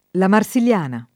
Marsiliana , la [ la mar S il L# na ]